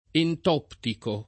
entoptico [ ent 0 ptiko ]